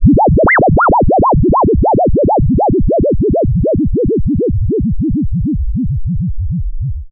Vinal Scratch Tutorial
ring.ogg